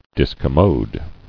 [dis·com·mode]